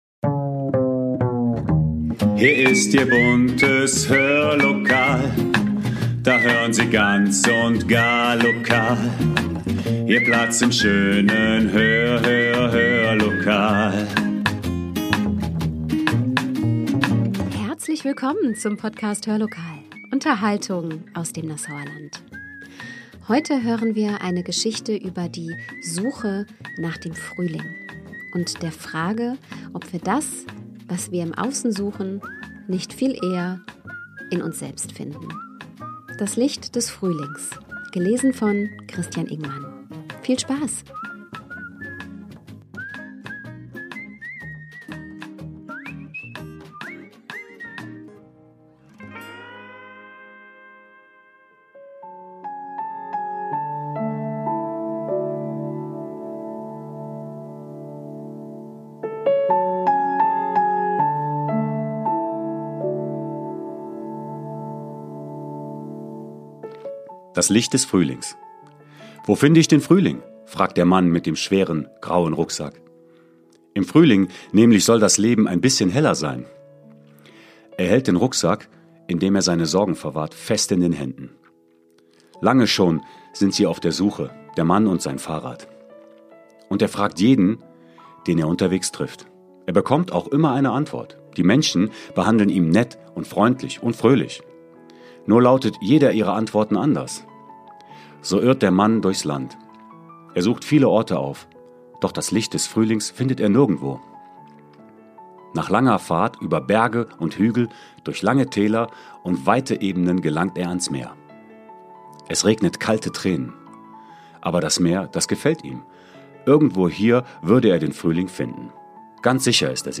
Eine Frühlingsgeschichte - gelesen